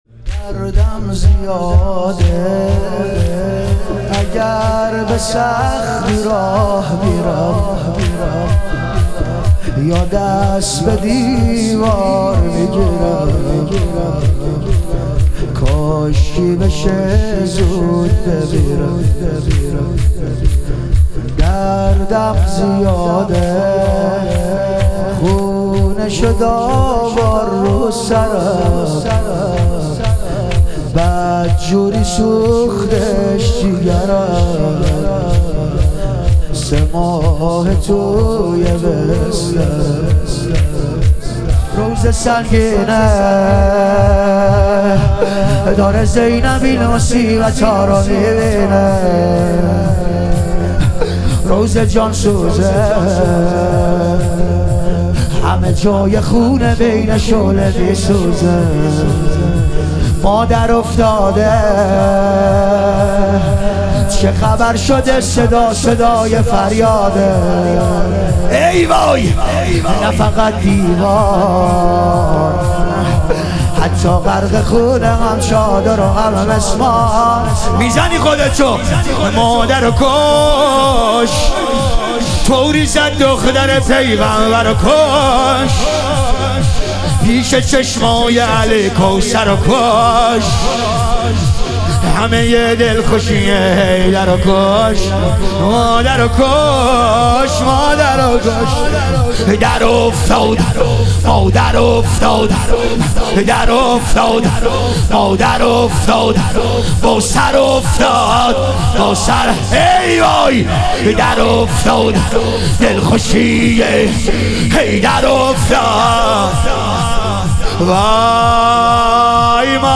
ایام فاطمیه دوم - لطمه زنی